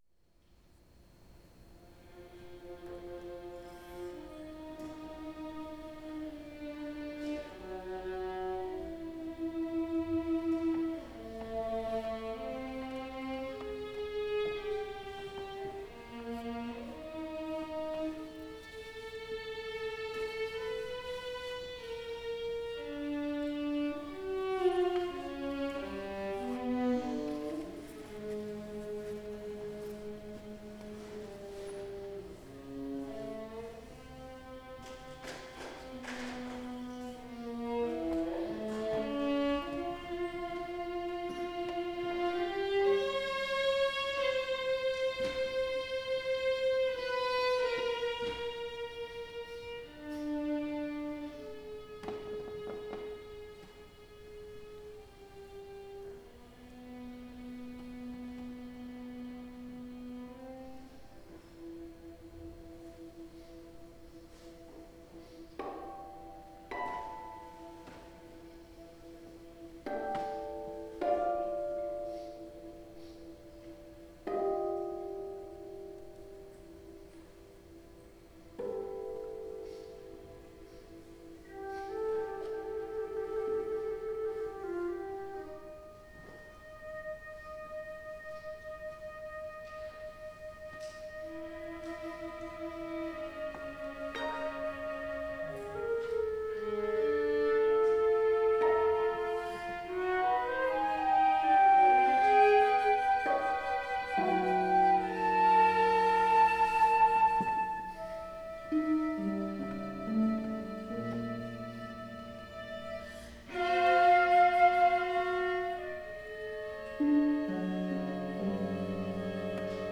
for flute, viola, and harp